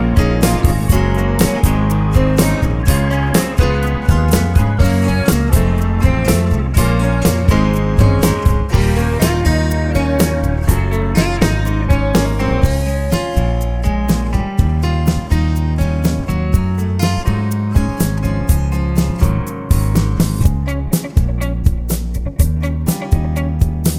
no Backing Vocals Jazz / Swing 3:31 Buy £1.50